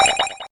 notify.ogg